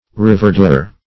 Reverdure \Re*ver"dure\, v. t. To cover again with verdure.